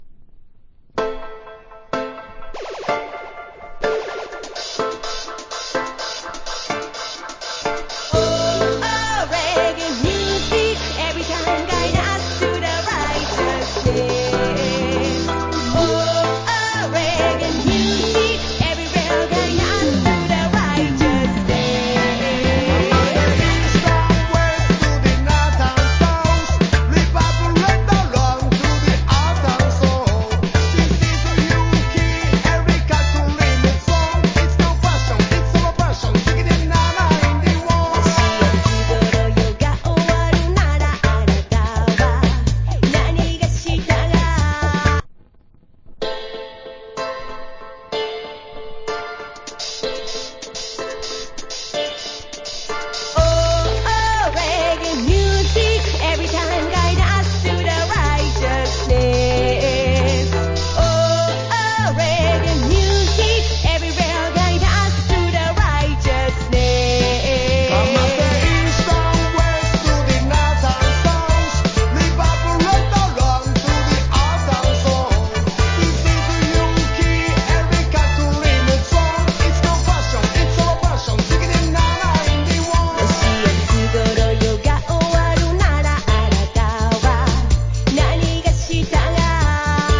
ROOTS ROCK JAPANESE ARTISTS
Reggae singjey
ROOTS ROCK